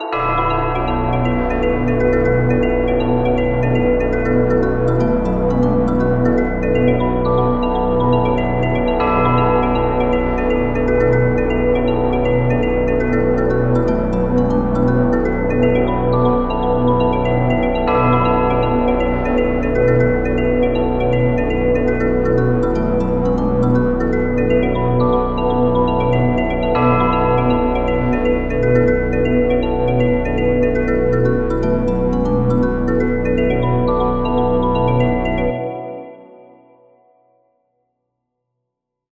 • We Don't Got It! The Team 71 Anthem Remix!